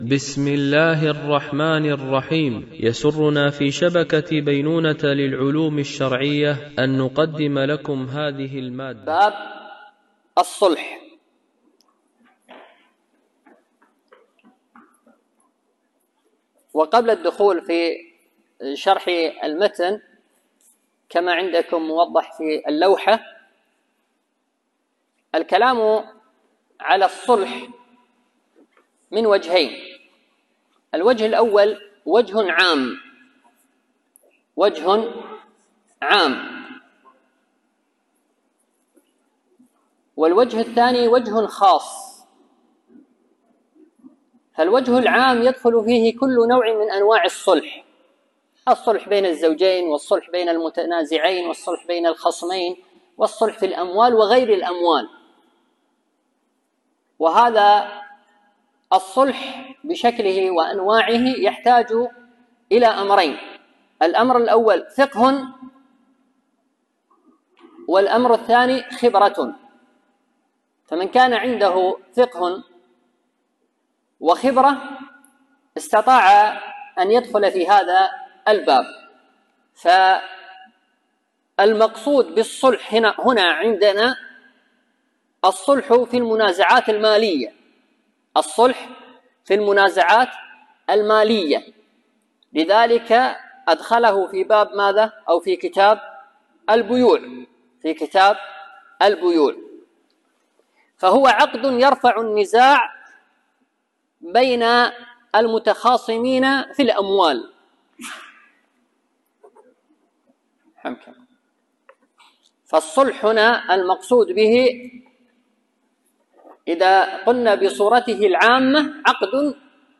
الدرس 59
Mono